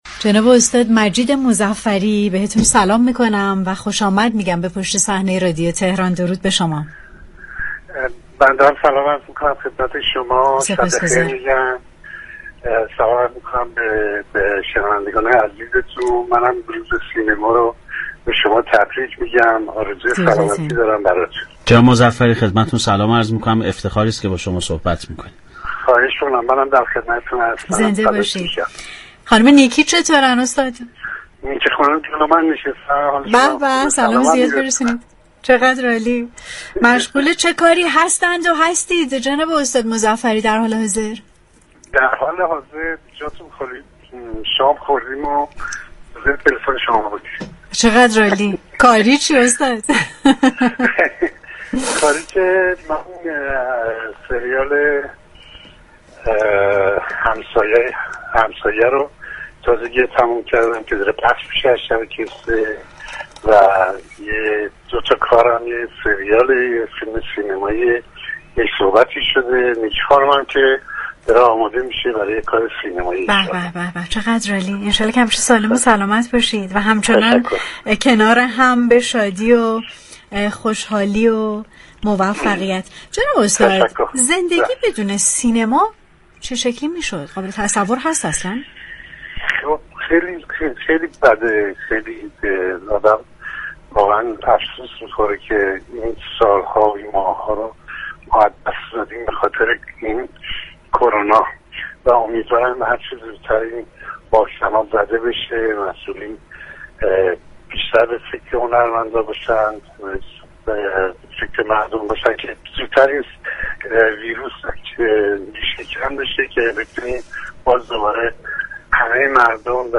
در گفتگوی تلفنی با برنامه پشت صحنه رادیو تهران